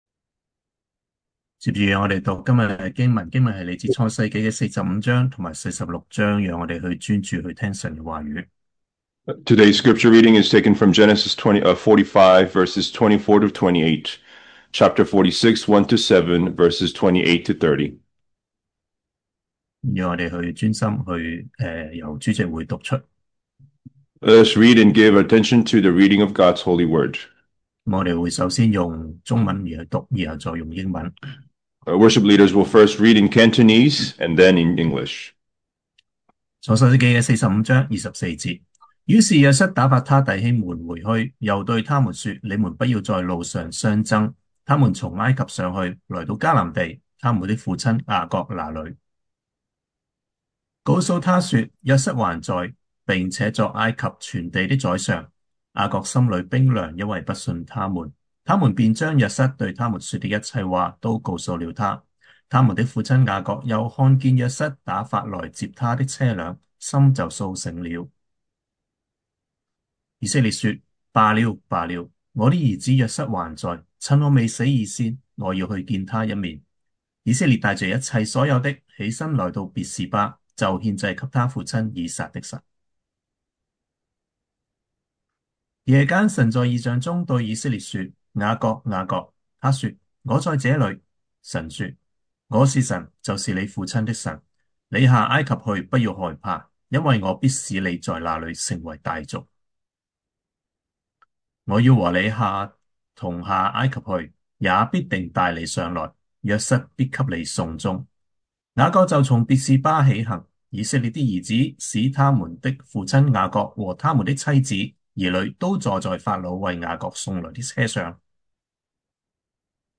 Genesis 46:28-30 Service Type: Sunday Morning Here I Am!